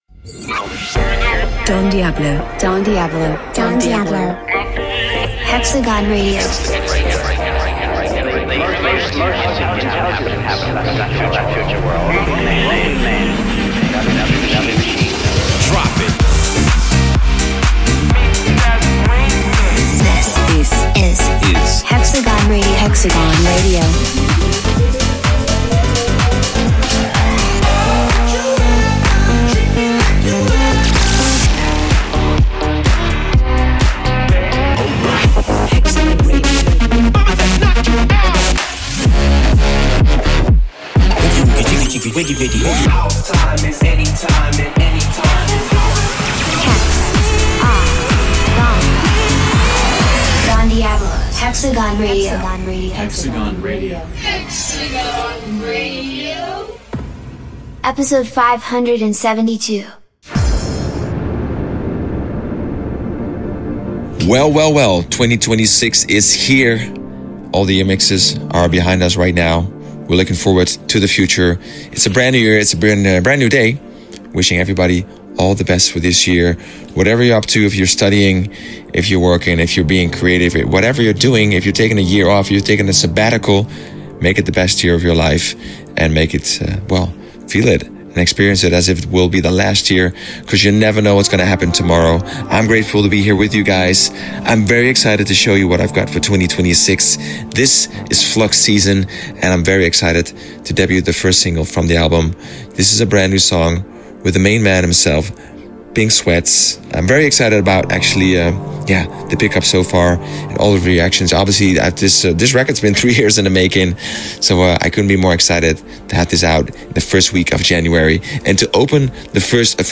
music DJ Mix in MP3 format
Genre: Bass House